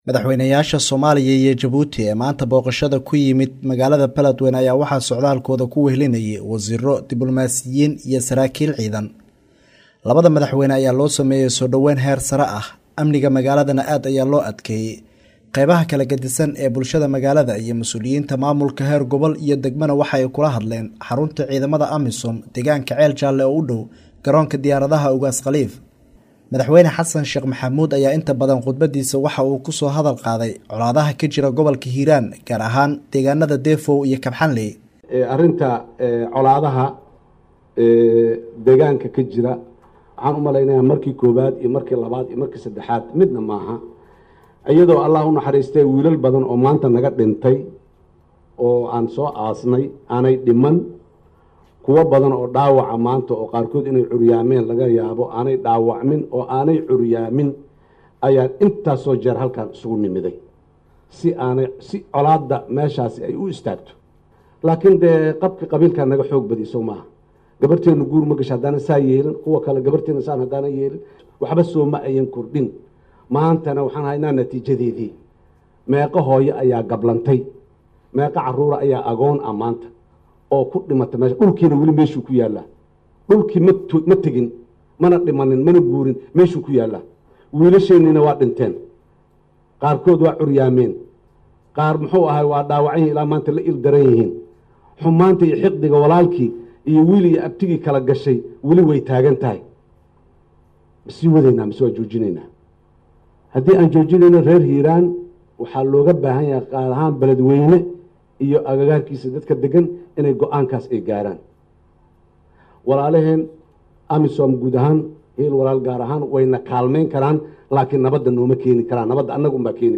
Dhageyso warbixinta Safarka labada Madaxweyne